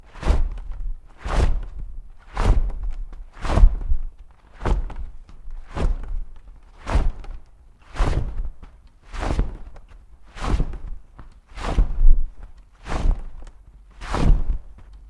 На этой странице собраны умиротворяющие звуки Бога и ангелов — небесные хоры, божественные мелодии и атмосферные треки для медитации, релаксации или творческих проектов.
Звук широких крыльев в полете